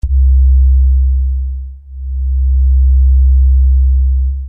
Virus_SubBass6.mp3